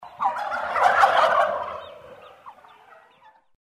Звуки индюков